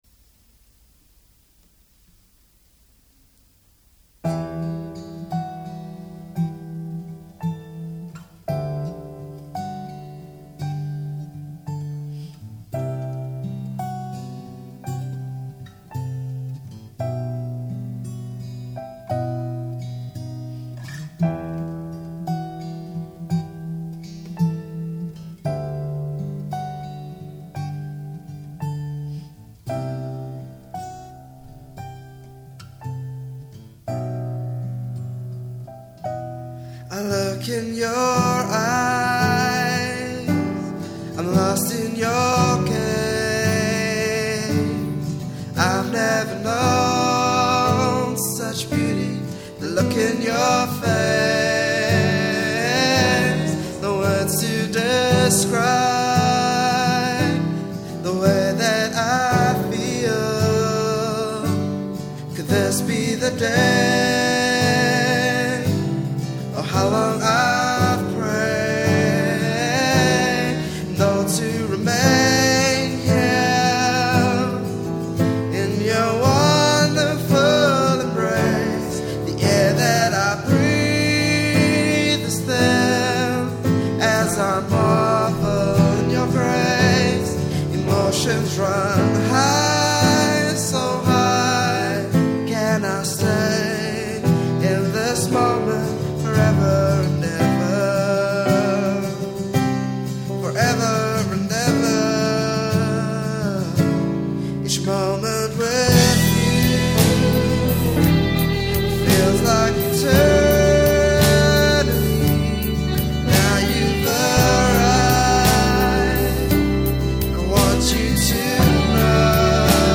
My friend's song - Recorded on my BOSS BR600
The bass may sound a little horrible cuz we used my electric guitar into the bass modeler on the BR600.